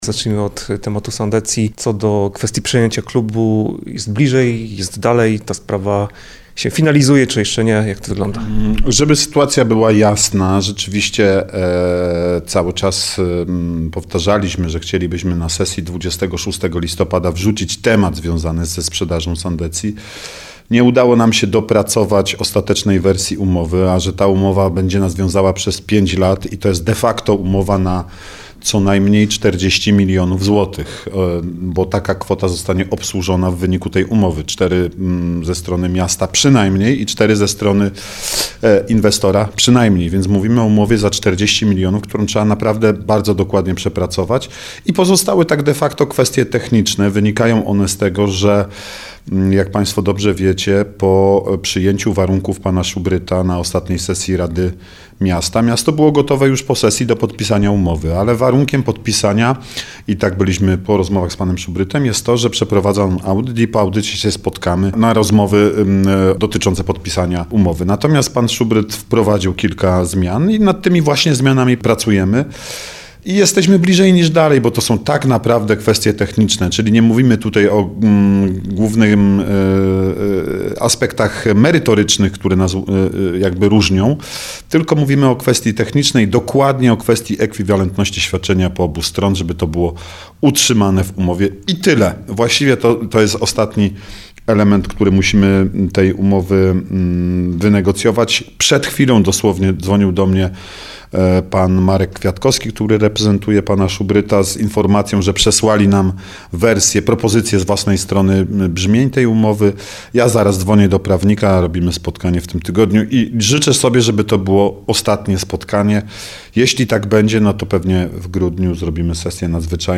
Z Arturem Bochenkiem rozmawialiśmy o sytuacji w Sandecji.